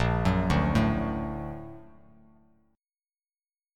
Bb+M7 chord